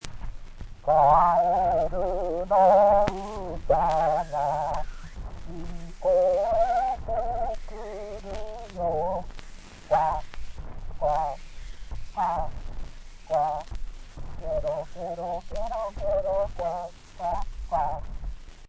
最後に、この蓄音機で記録したディスクを、普通のレコードプレイヤーで再生してみた。
完成した“マイレコード”をレコードプレーヤーで
再生（kerokero.mp3）
回転数が違うから、音程は狂っているけど、まぁこんなもん。